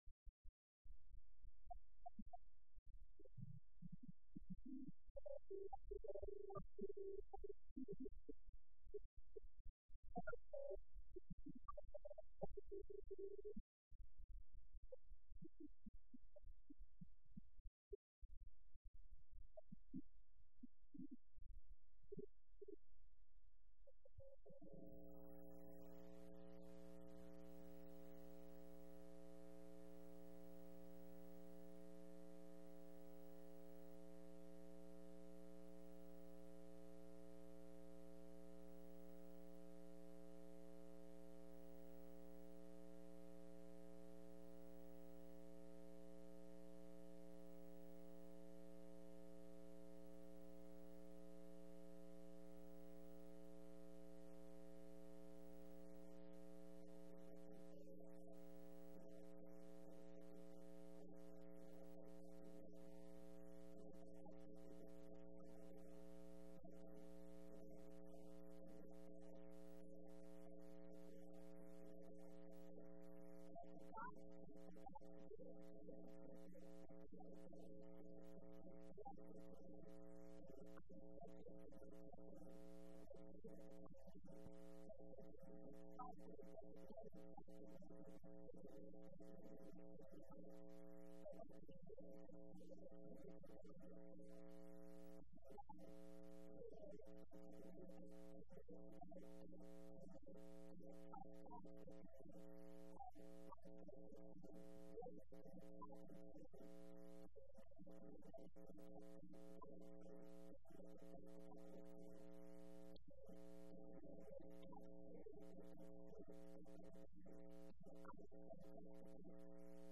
03/14/12 Wednesday Evening Service